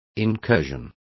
Complete with pronunciation of the translation of incursion.